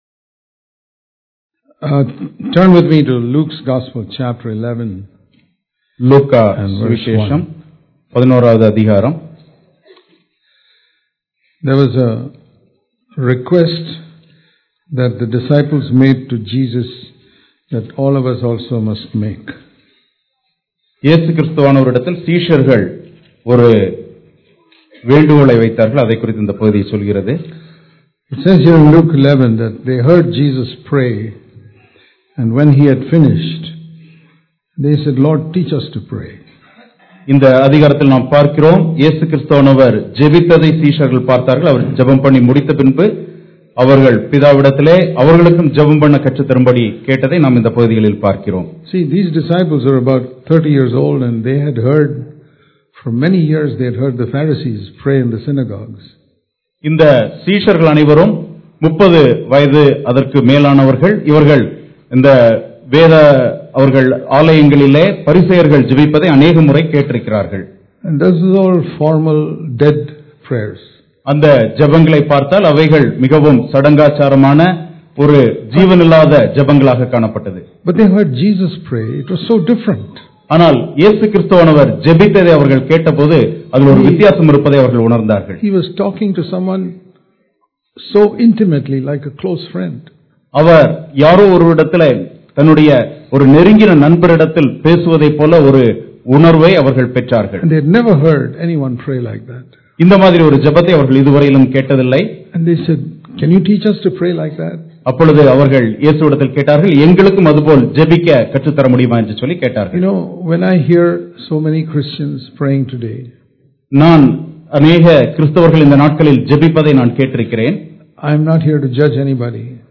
Praying The Way Jesus Taught Dubai Special Meetings 2018 Speaker
Sermons